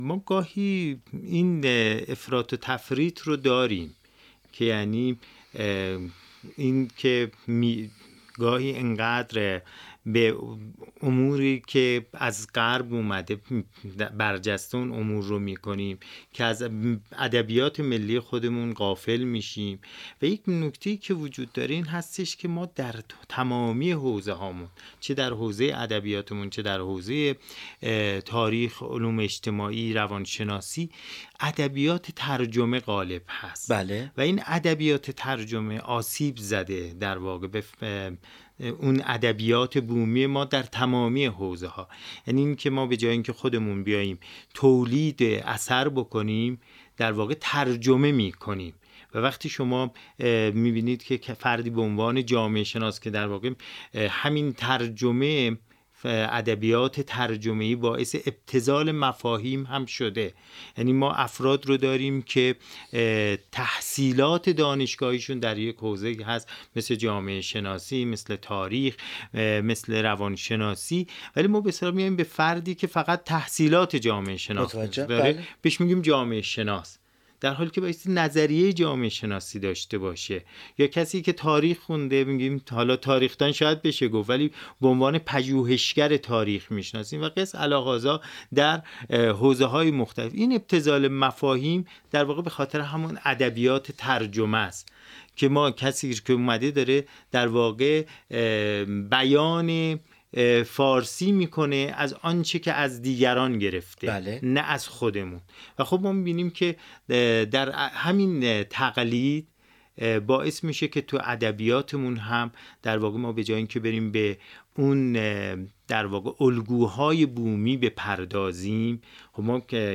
بنای این گفت‌وگو روایتی صریح و بی‌پرده بود و در پاره‌ای از فرازهای این گفت‌وگو به‌ سبب زاویه‌هایی که گفتار دو مهمان این میزگرد داشتند، بحث‌های گرمی درگرفت که همراهی با این گفت‌وگو را برای هر مخاطبی جذاب‌تر می‌کند.